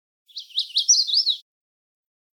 Song of the Magnolia Warbler